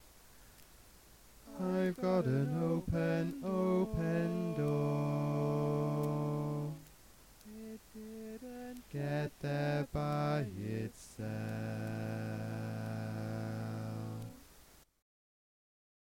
Key written in: A Major
Type: Barbershop